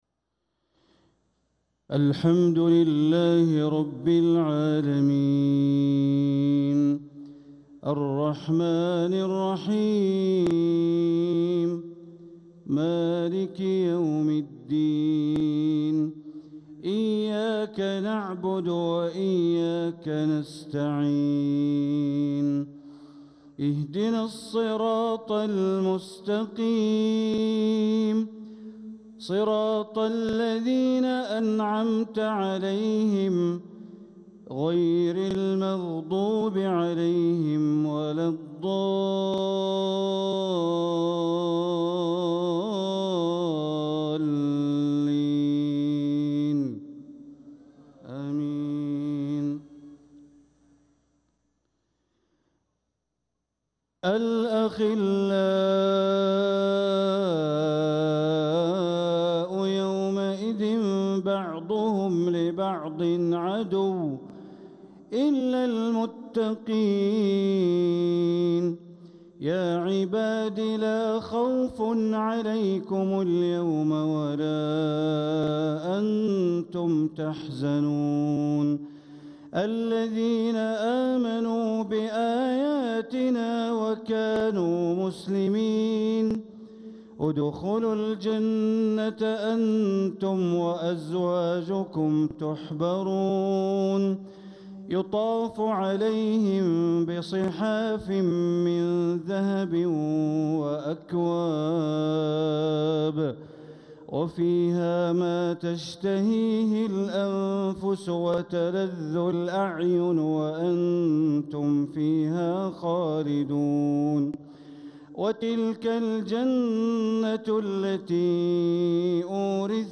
تلاوة لخواتيم سورة الزخرف عشاء الأربعاء ٢٨محرم١٤٤٧ > 1447هـ > الفروض - تلاوات بندر بليلة